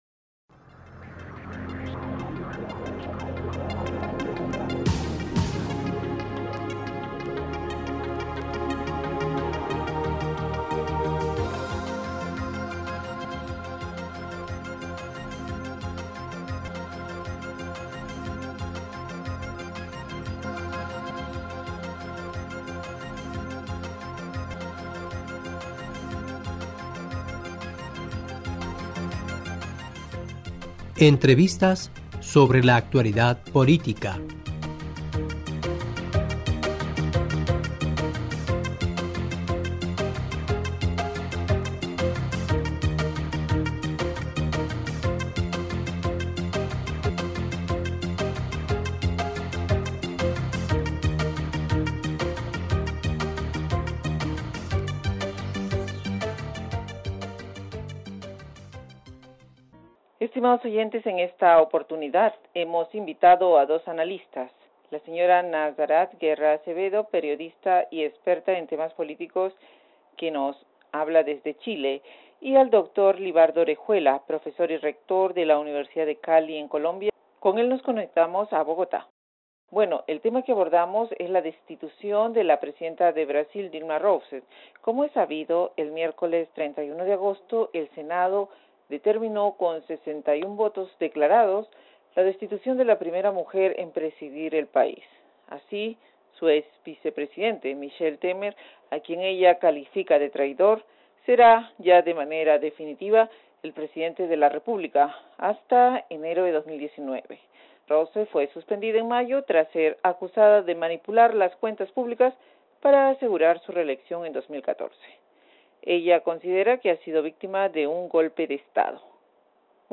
E: Estimados oyentes en esta oportunidad hemos invitado a dos analistas